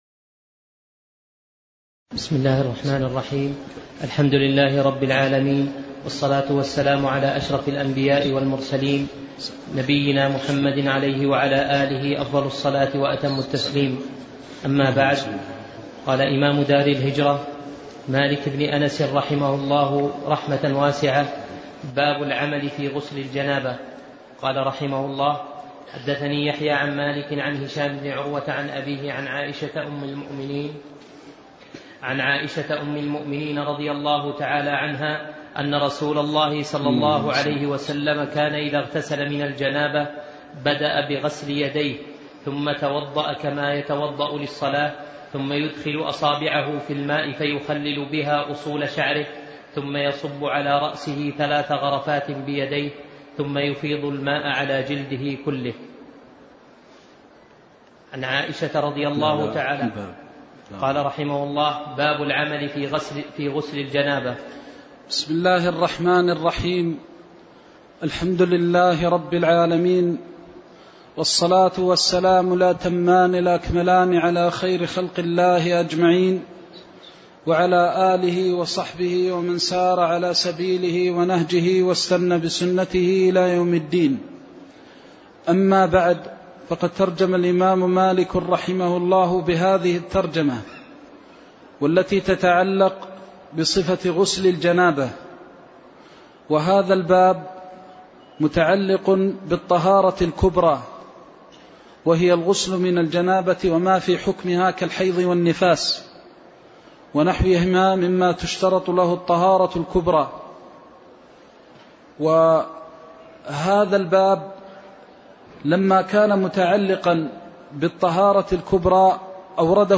الدرس السابع عشر من قول المصنف رحمه الله :باب العمل في غسل الجنابة